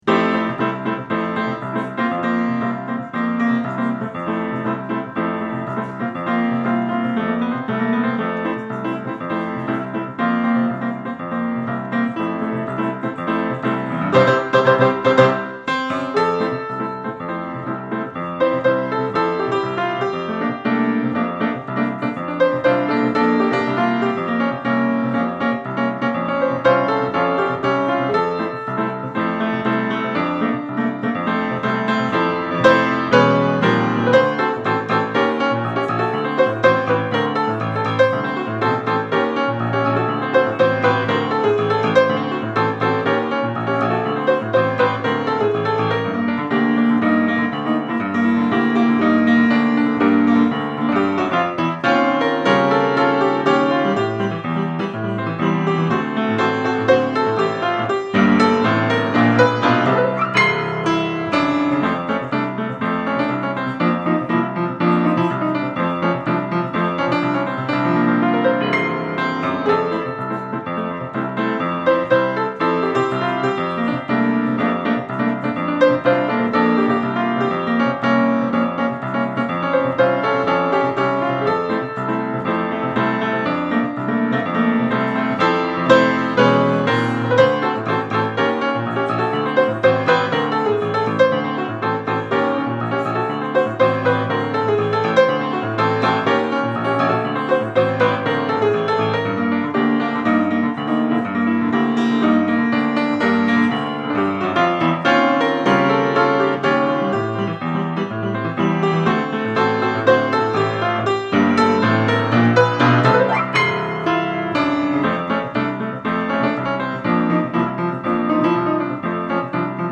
Easy Listening
Cocktail Music
Piano Jazz , Solo Piano